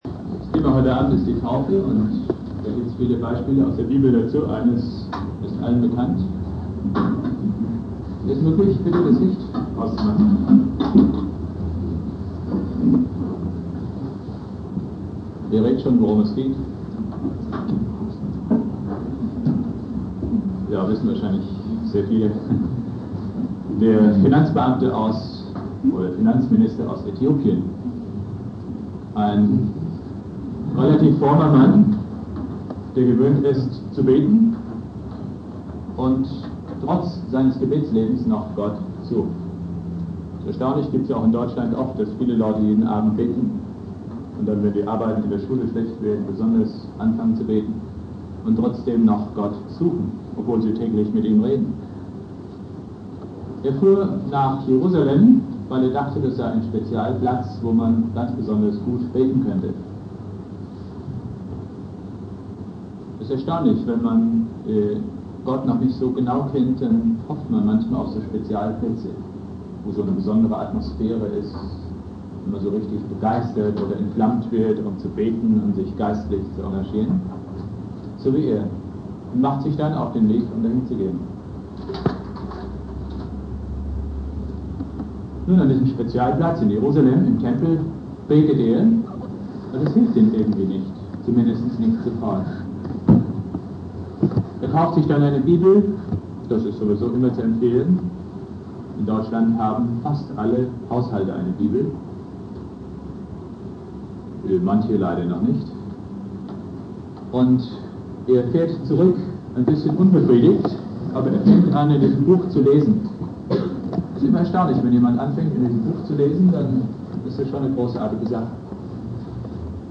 Thema: Thema "Taufe" (Offener Abend) Bibeltext: Apostelgeschichte 8,26-40 Predigtreihe: Lehrabende / Seminare Dauer